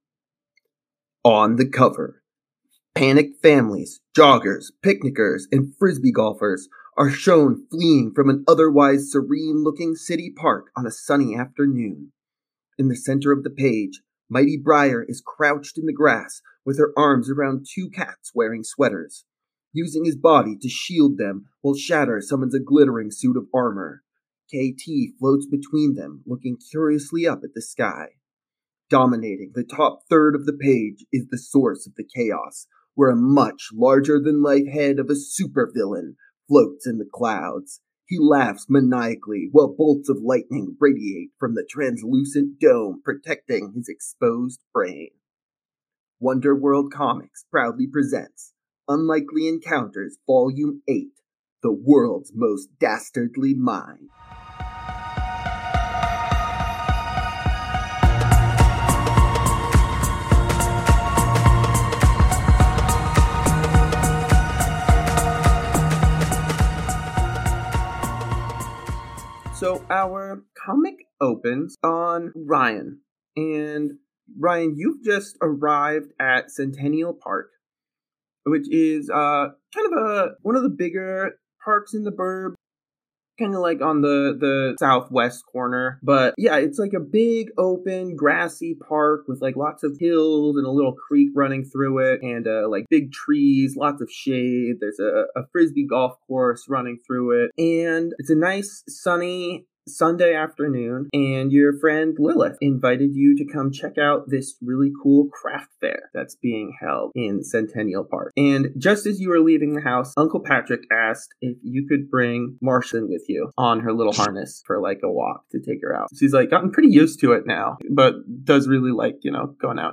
Welcome to Wonder World Comics, an actual play podcast of Masks: A New Generation! Witness the Midnighters do battle with time traveling villains, wrangle rampaging hormones, and discover what it means to be a hero. Please excuse the brief audio issues in our first few episodes while we get our feet under us, the audio quality is much better in our future issues